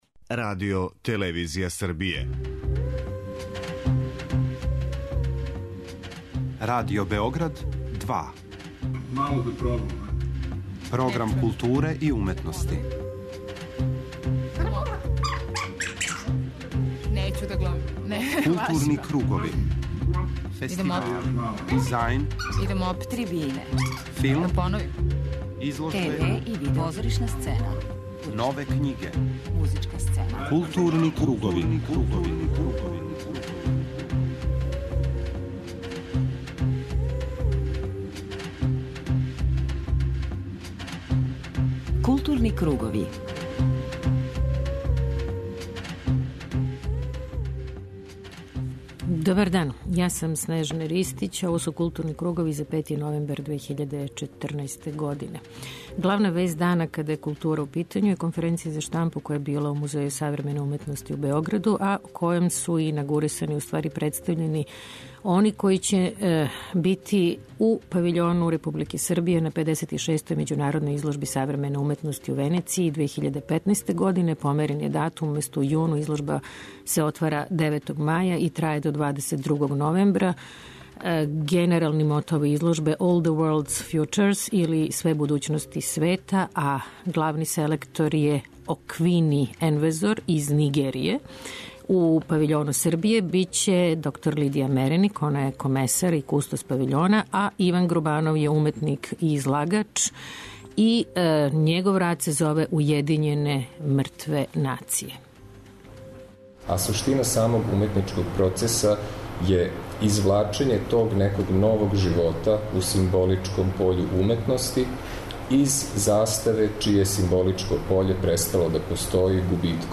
преузми : 54.22 MB Културни кругови Autor: Група аутора Централна културно-уметничка емисија Радио Београда 2.